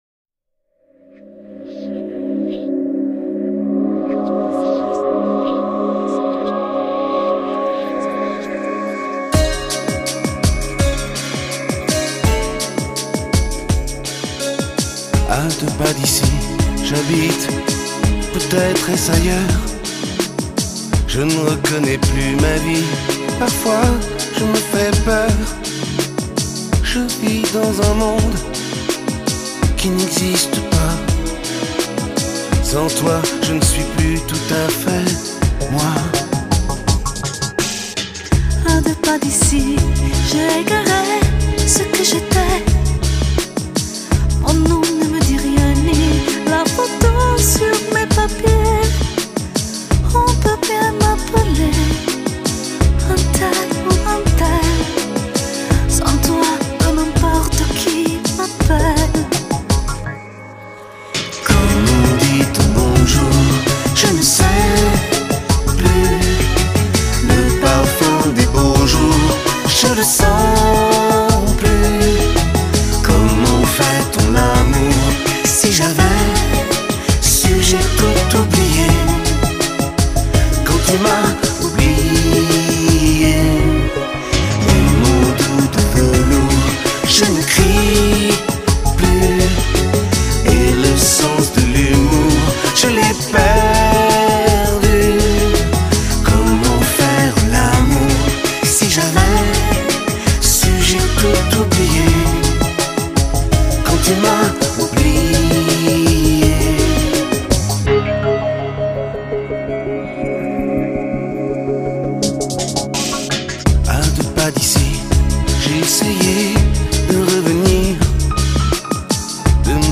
法语香颂